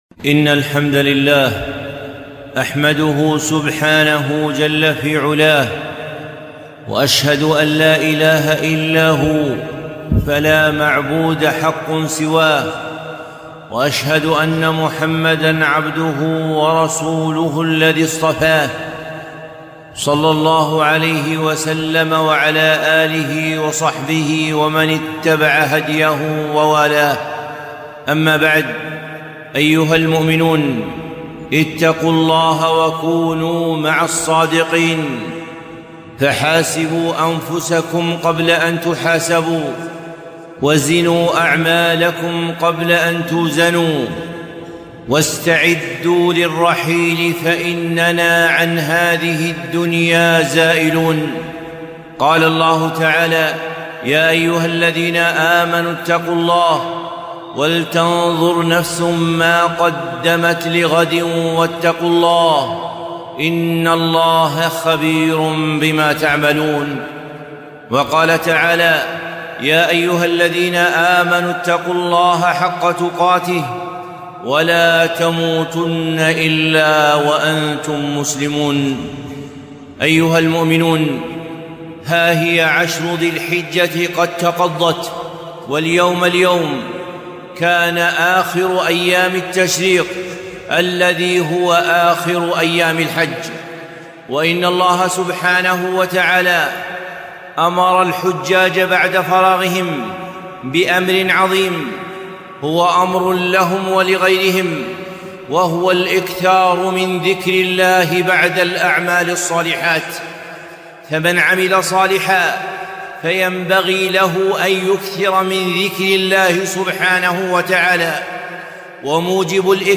خطبة - ماذا بعد الحج والأضحى؟ 1442